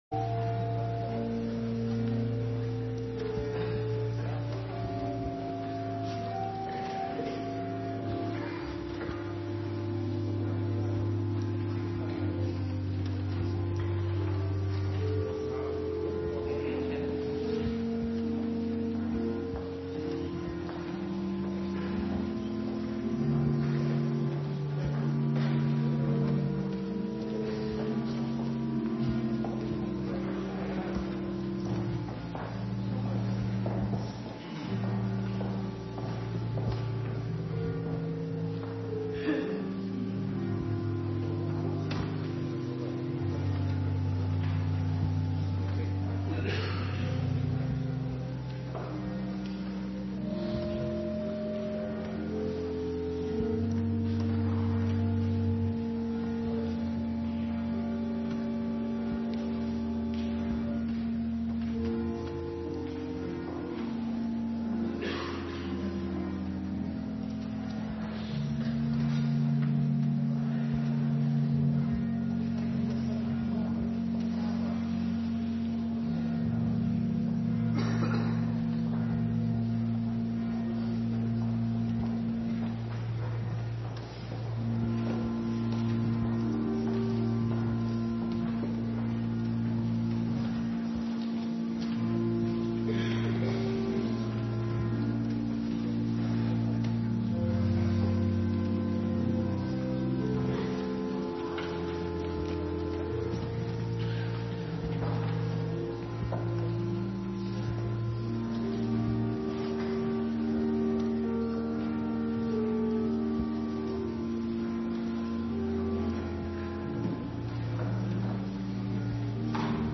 Avonddienst Eerste Kerstdag
Locatie: Hervormde Gemeente Waarder